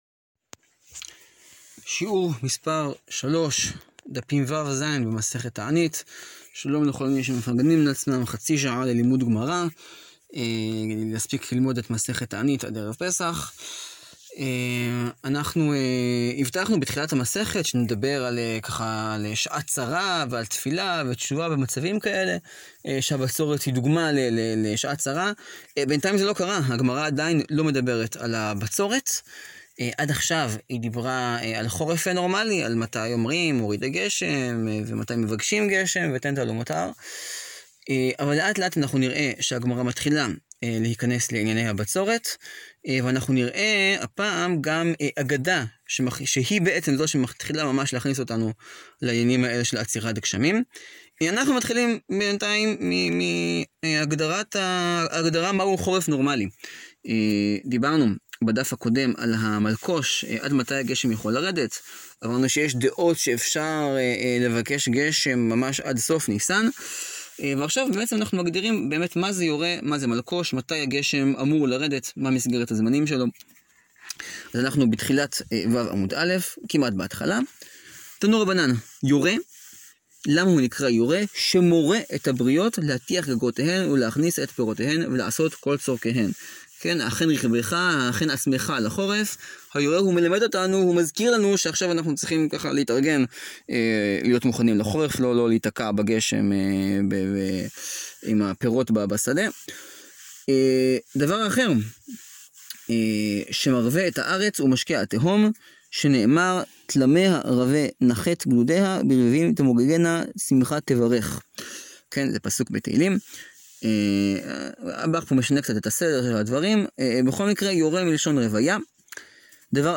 לימוד 14 יום: שיעור 3 – תענית ו-ז